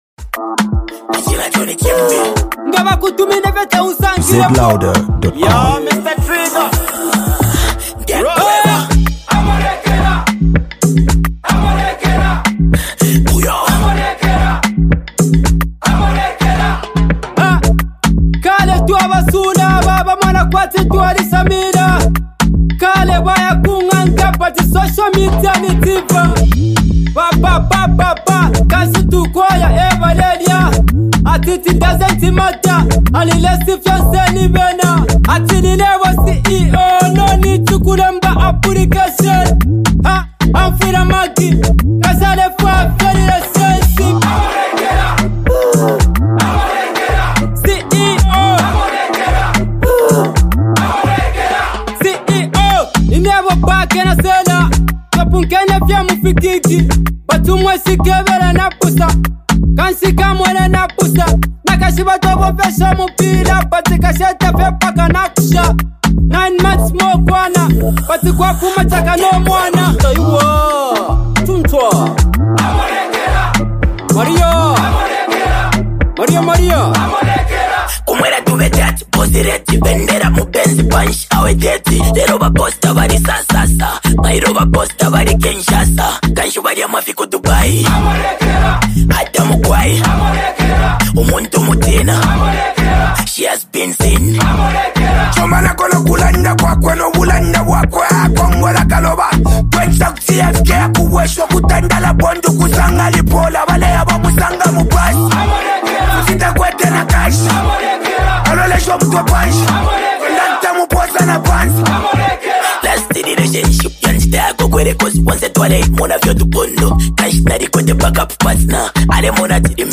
Here comes a zambian multi talented copperbelt bassed raper
zambian music duo